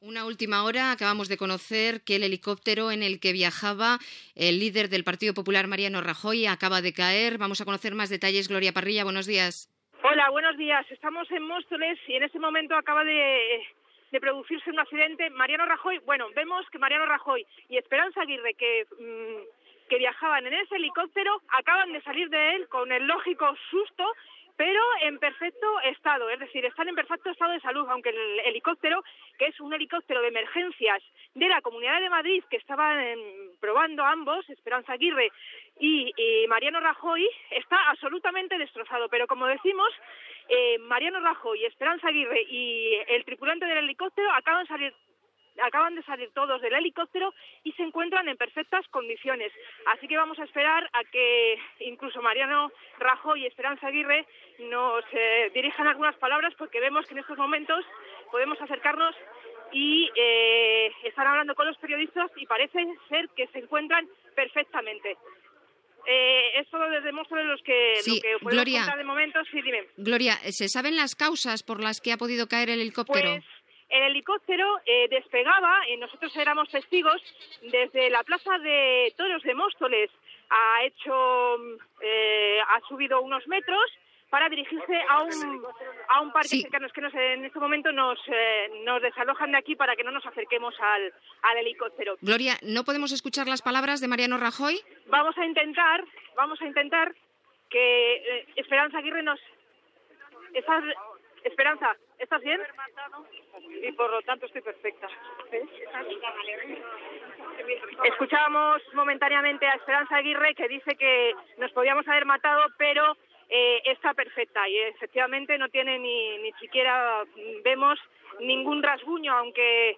Connexió amb la plaça de braus de Móstoles (Madrid), on ha caigut l'helicòpter que transportava a Mariano Rajoy, president del Partido Popular, i Esperanza Aguirre, presidenta de la Comunidad de Madrid
Informatiu